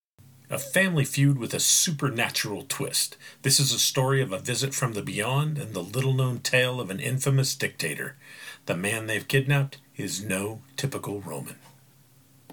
I'm a middle aged American male with a dynamic voice and a lot of heart.
Short Narration of TV or documentary
Slight Southern. Some SW England. Some upper class London
Narrator and VO_0.mp3